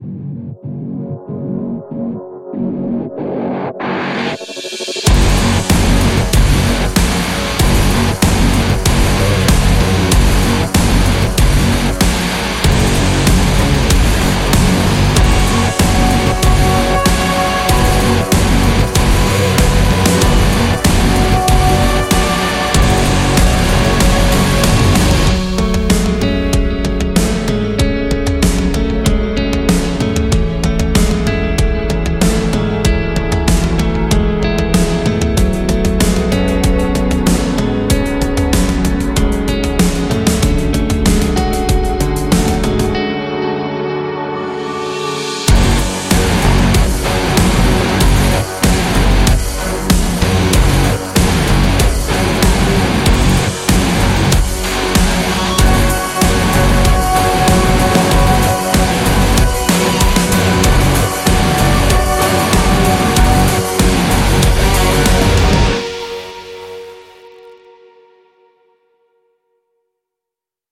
Heavyocity Damage Guitars 是一款爆炸性的吉他音源，提供了精心制作的重型吉他音色、令人融化的吉他段落、清新的清音吉他和无瑕的演奏，充满了节奏和能量，可以为你的配乐增添活力和魅力。
Damage Guitars 拥有超过 1200 个声源，都存放在一个强大而直观的引擎中，提供了一个前所未有的吉他声音阵容，包括凶猛的段落、闪耀的清音和令人兴奋的过渡元素，以一种可玩、直观和灵活的格式带到你的指尖。